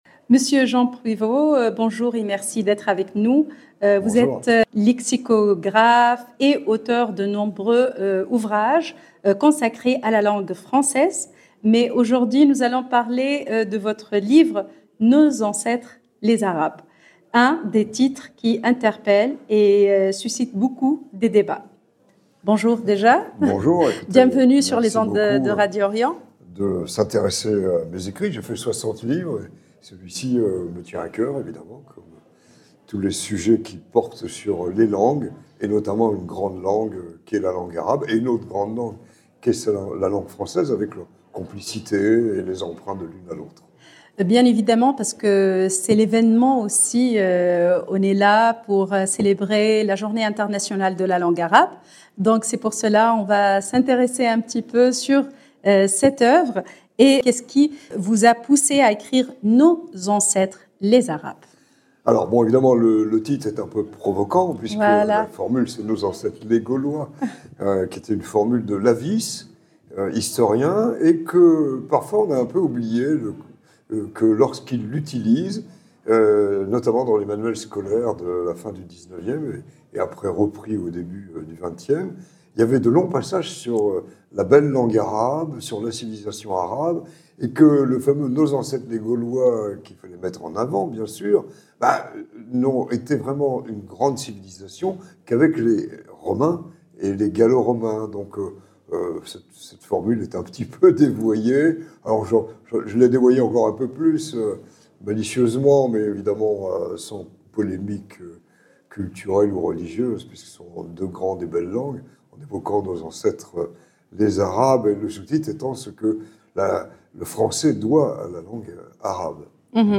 Une rencontre qui interroge la mémoire ,transmet le savoir et met en lumière des œuvres fondatrices ayant contribué à façonner notre histoire commune . Un échange passionnant à découvrir et à partager 0:00 14 min 20 sec